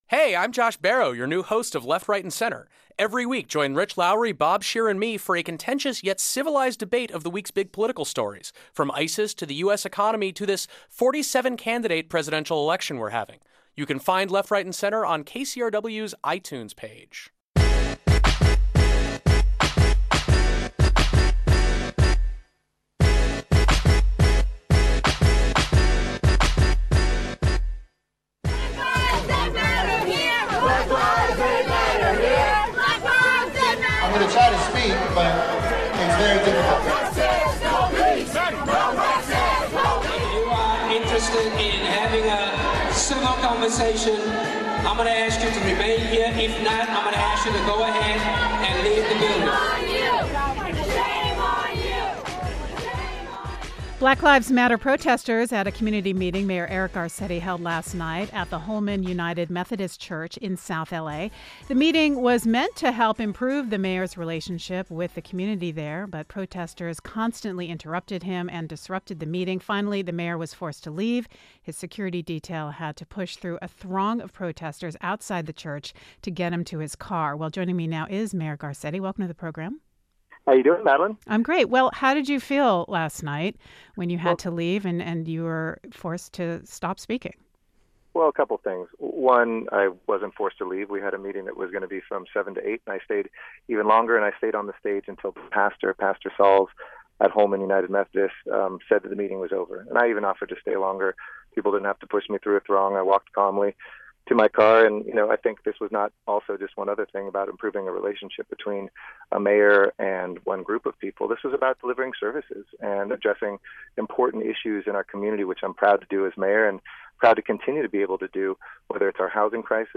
We talk to the mayor and a protest organizer.